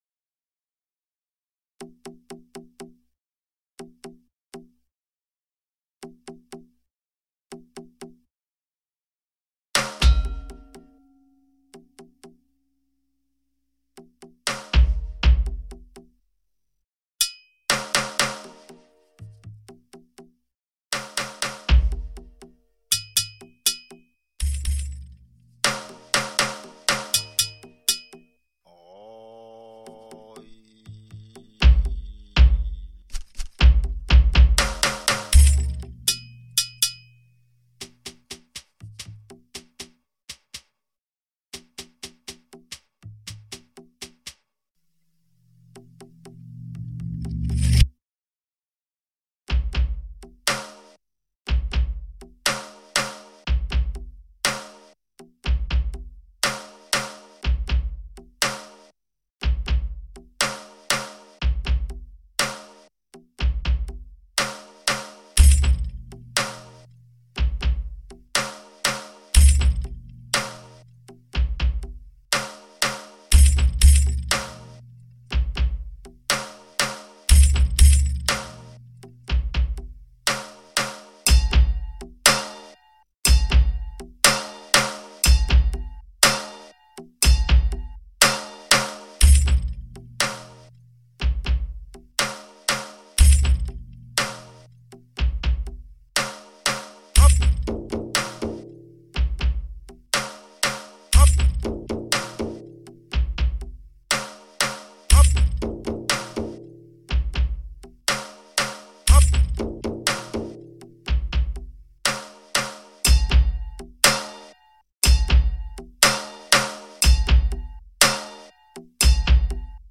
ステレオ